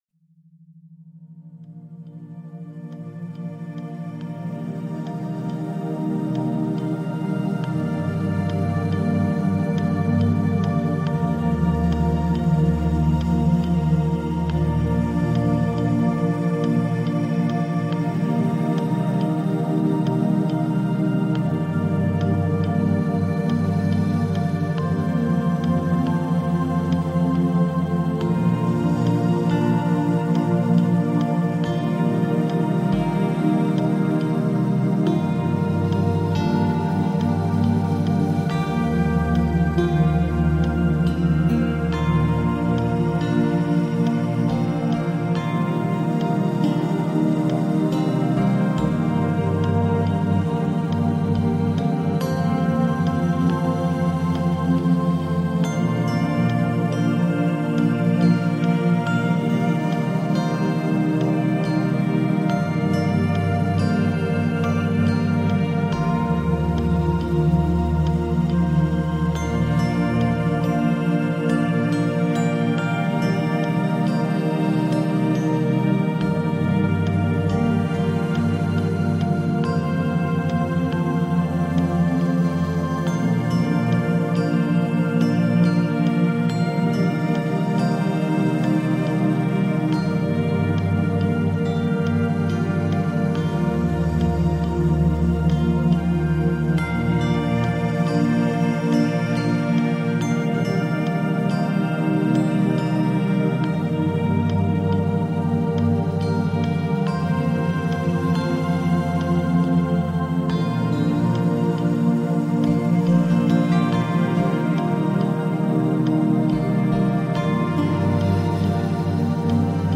Café de Séoul naturel · méthode 50-10 scientifique pour étude structurée